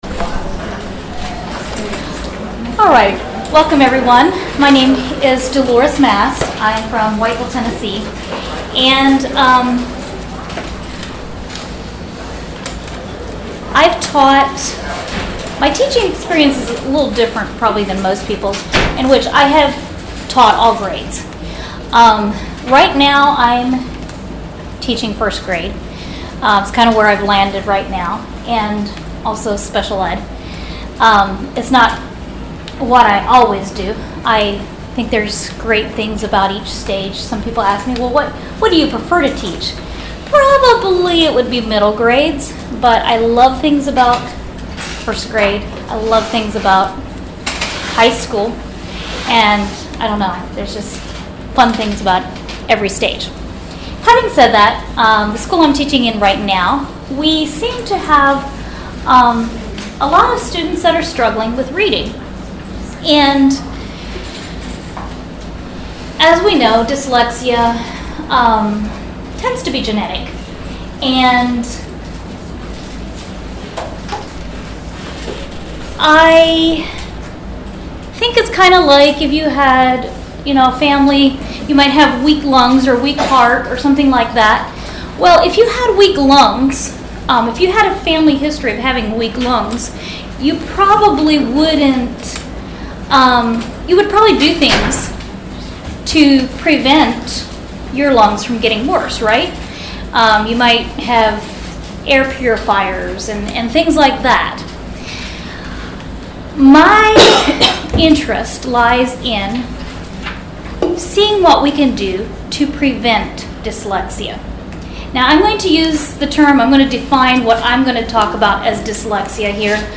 We will explore the different threads of teaching a child to read and how to determine which ones are missing when students struggle. This workshop is geared to first through third grade general classrooms.